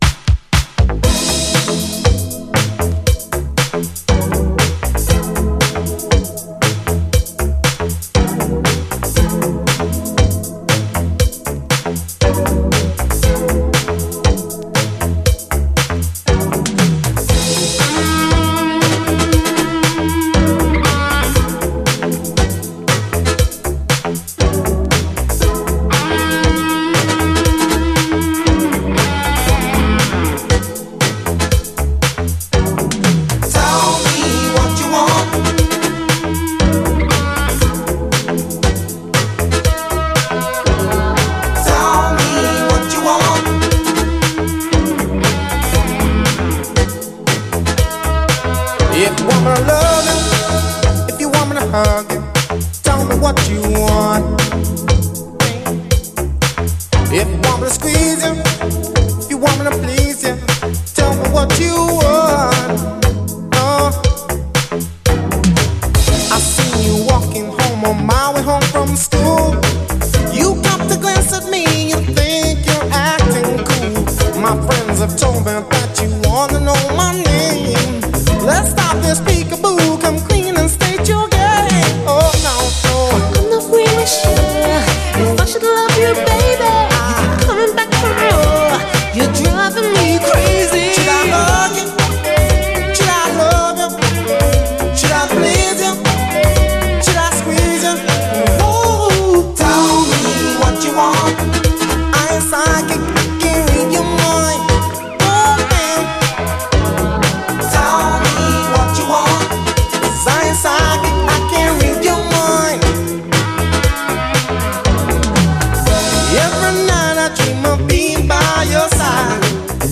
SOUL, 70's～ SOUL, DISCO
スムース＆アーバンなUKシンセ・ブギー・ファンク！
スムースでお洒落なアーバン・シンセ・ブギー・ファンクです！